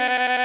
6 Harsh Realm Vox Repeat.wav